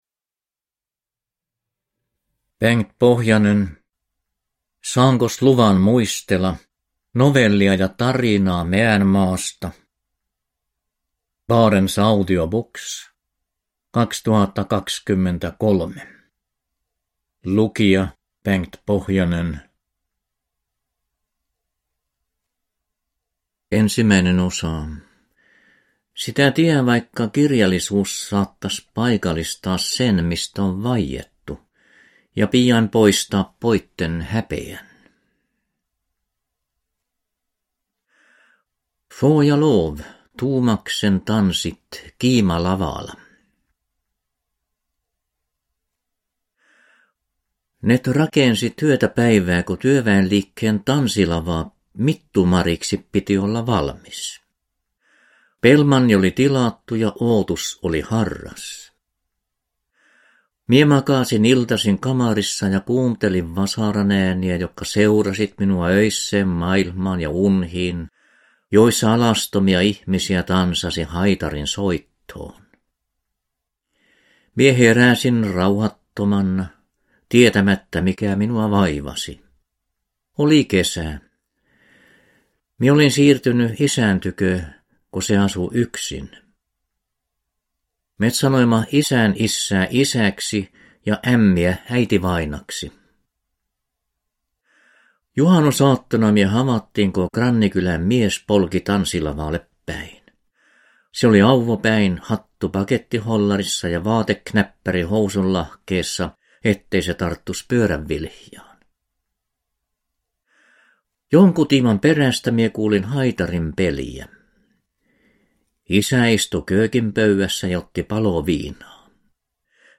Saankos luvan muistela – Ljudbok – Laddas ner